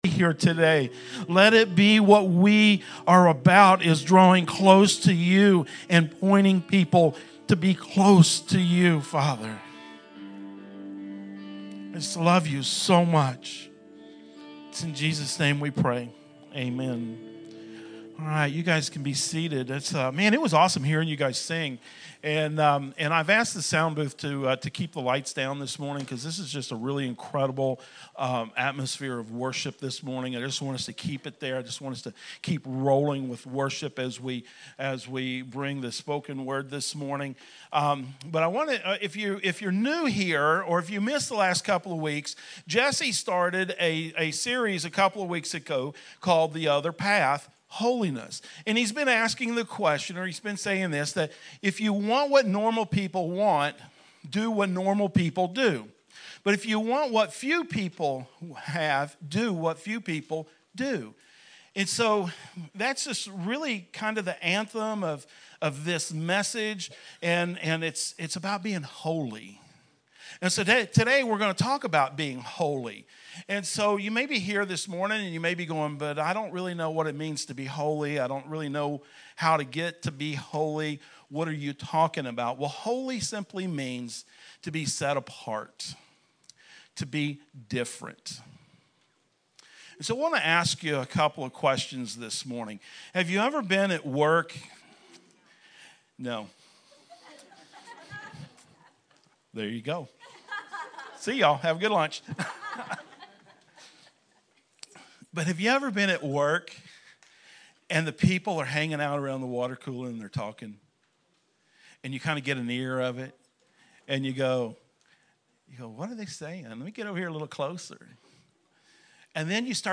Two Rivers Bible Church - Sermons